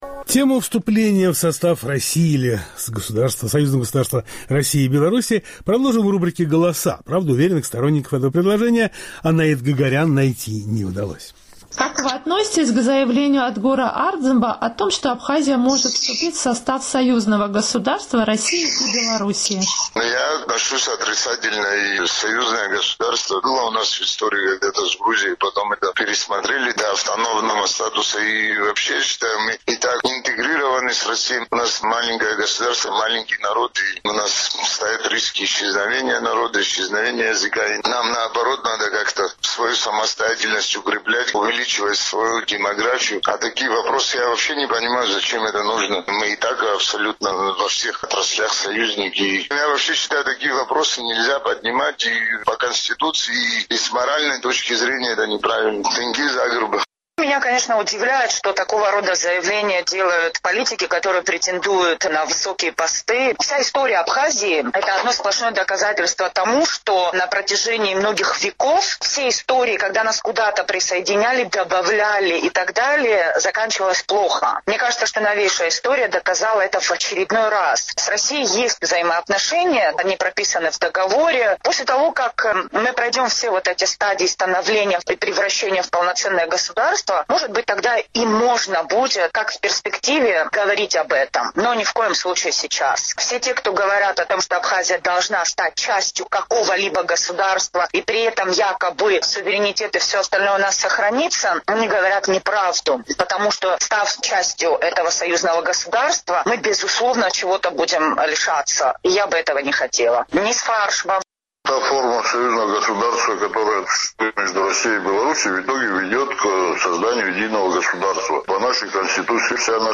Помимо темы вступления в состав России обсуждается в Абхазии и тема вступления в союзное государство России и Беларуси, с идеей которого выступил лидер оппозиции Адгур Ардзинба. Правда, уверенных сторонников этого предложения среди опрошенных нами жителей Абхазии найти не удалось.